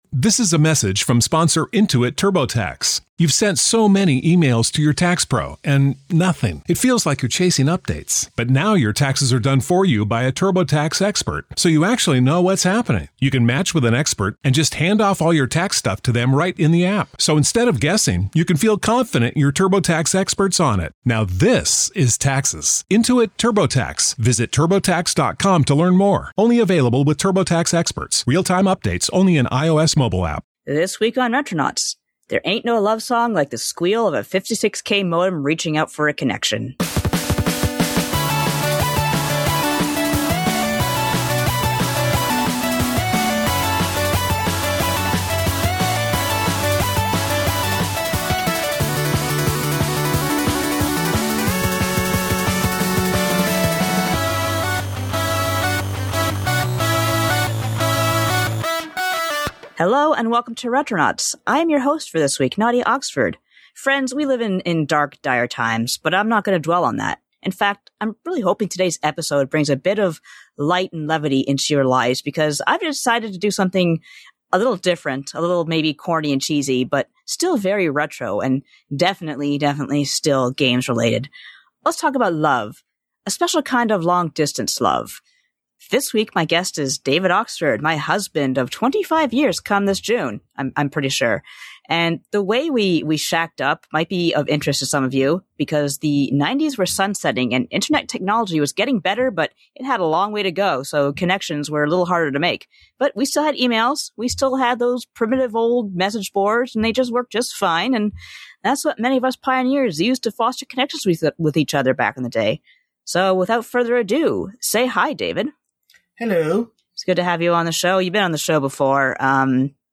*shrieking modem noises*